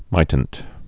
(mītnt)